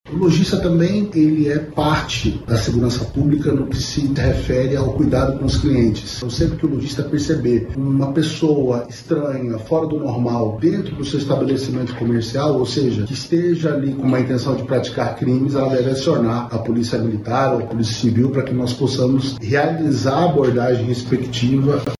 As autoridades pedem que os comerciantes colaborem com a segurança.